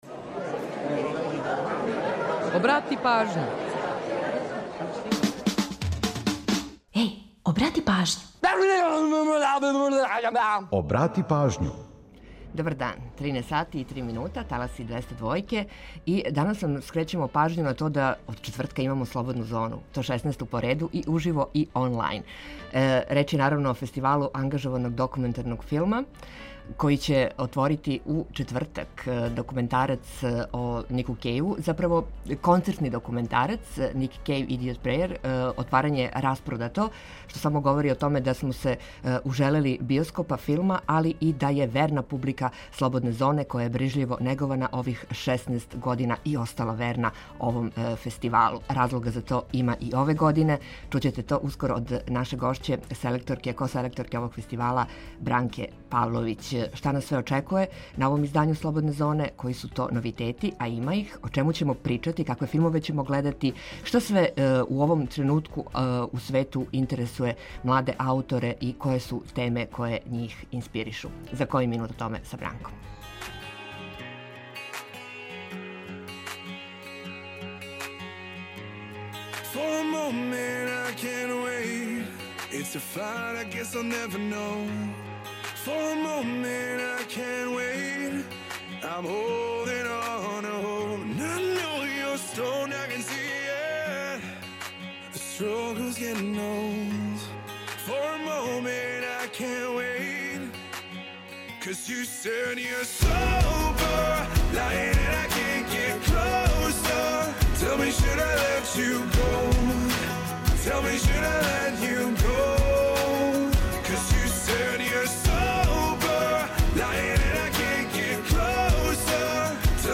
Ту је и пола сата резервисано само за музику из Србије и региона, а упућујемо вас и на нумере које су актуелне. Чућете и каква се то посебна прича крије иза једне песме, а за организовање дана, ту су сервисни подаци и наш репортер.